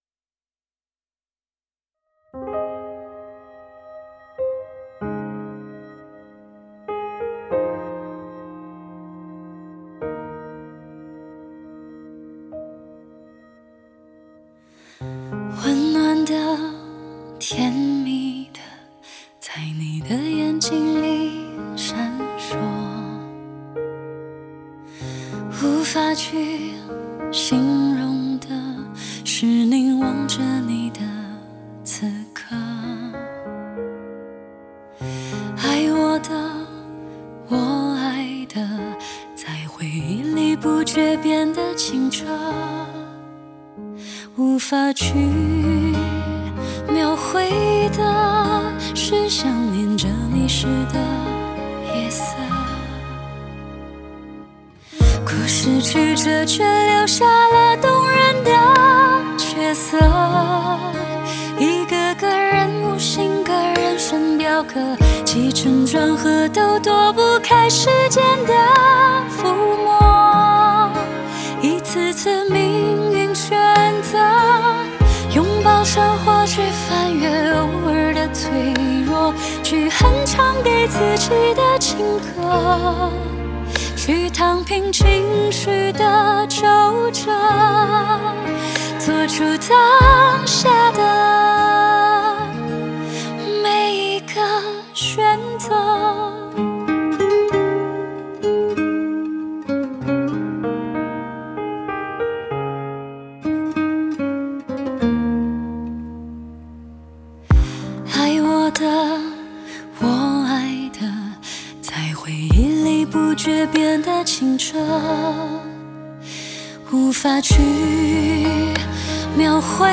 Ps：在线试听为压缩音质节选，体验无损音质请下载完整版
吉他
弦乐